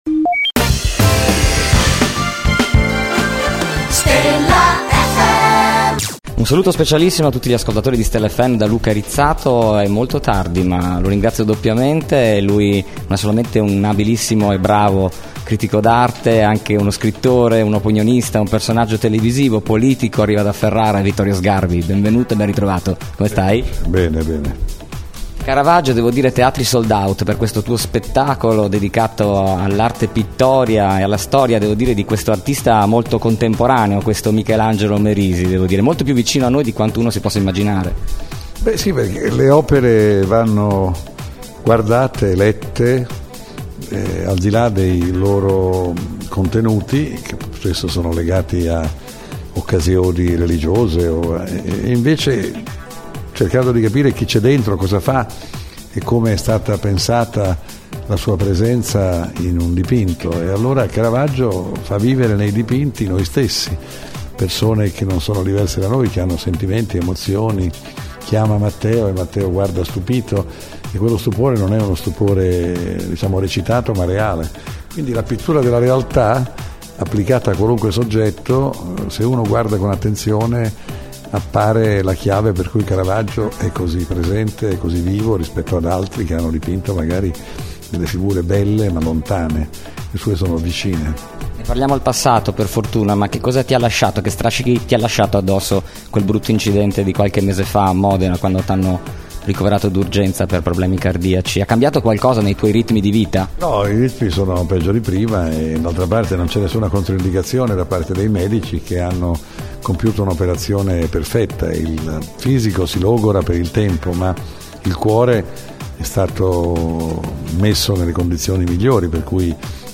Intervista Vittorio Sgarbi | Stella FM
Intervista-Vittorio-Sgarbi.mp3